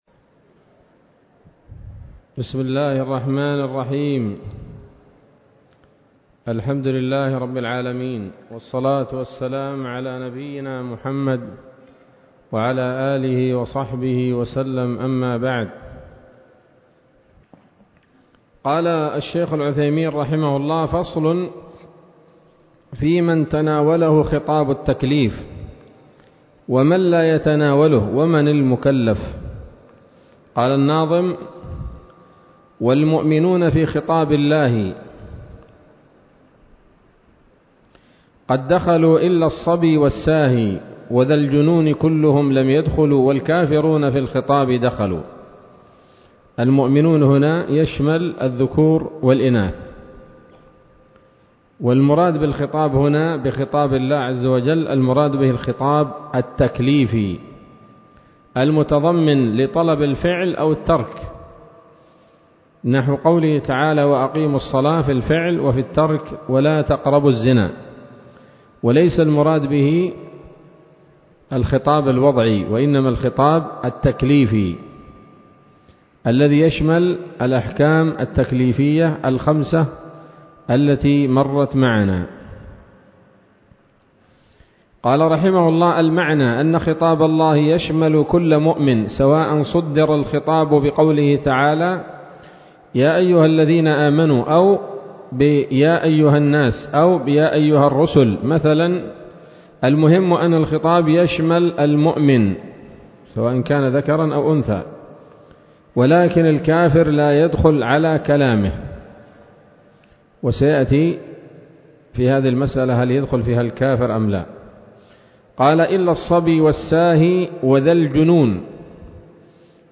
الدرس السادس والثلاثون من شرح نظم الورقات للعلامة العثيمين رحمه الله تعالى